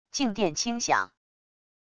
静电轻响wav音频